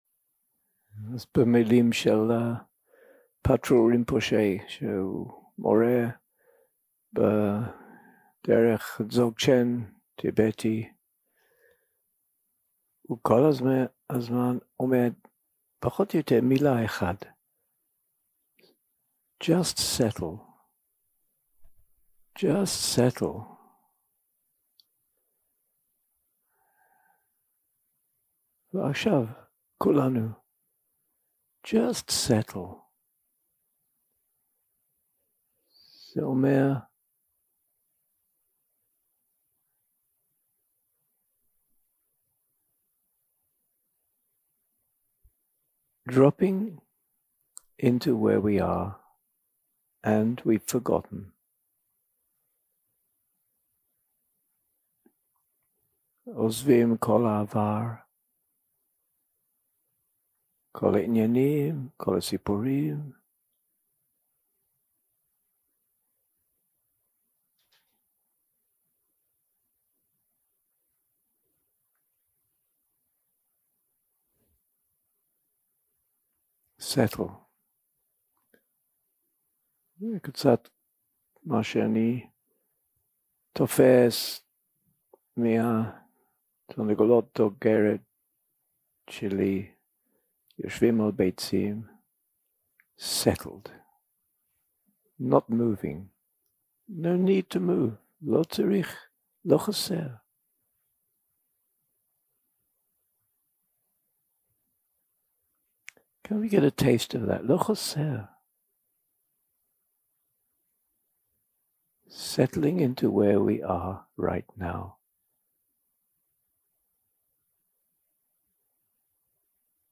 יום 5 – הקלטה 12 – צהריים – מדיטציה מונחית - A Kind Witness and Phrases 0:00 0:00 סוג ההקלטה: Dharma type: Guided meditation שפת ההקלטה: Dharma talk language: English